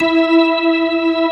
Index of /90_sSampleCDs/AKAI S6000 CD-ROM - Volume 1/VOCAL_ORGAN/POWER_ORGAN
P-ORG2  E4-S.WAV